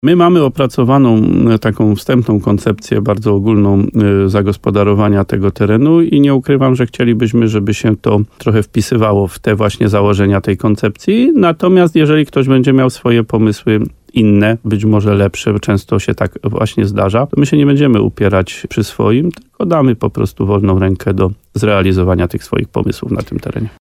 – Osoby, które chcą przygotować nad brzegami jeziora np. plaże czy inne tereny rekreacyjne mogą zgłaszać się do urzędu – mówi wójt gminy Łososina Dolna, Adam Wolak.